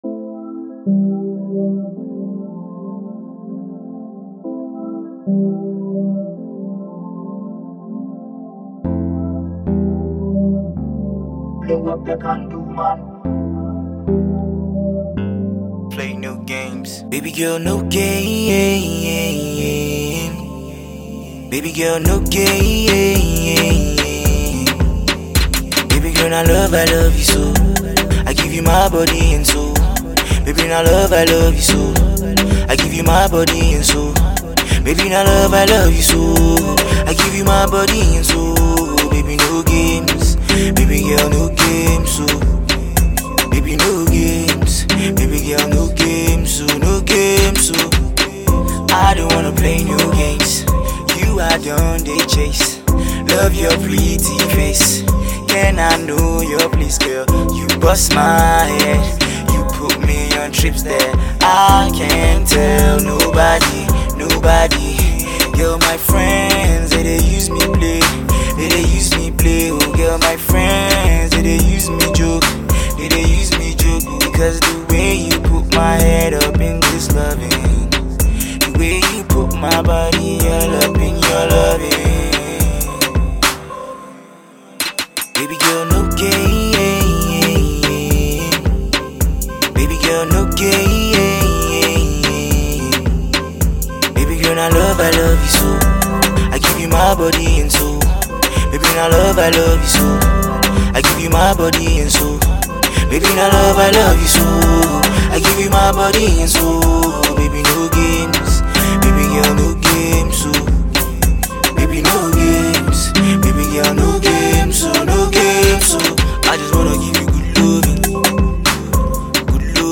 talented rapper/singer